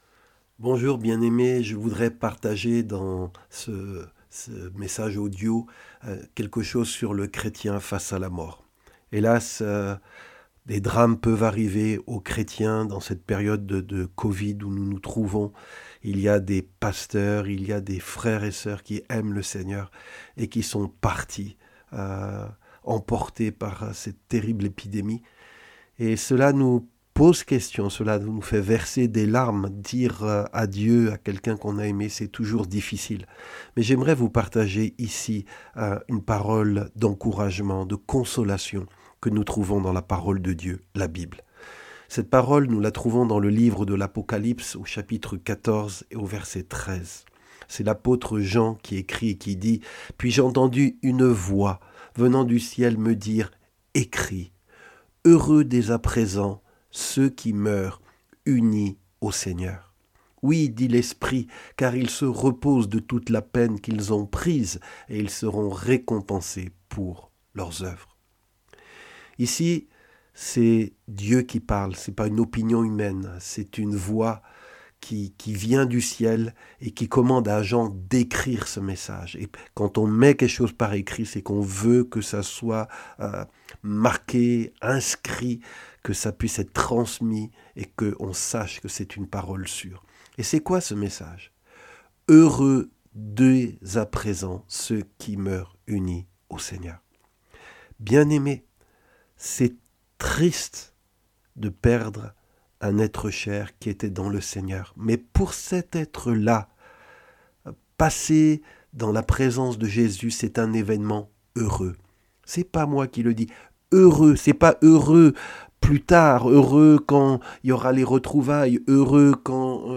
Des messages audio chrétiens